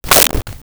Whip 04
Whip 04.wav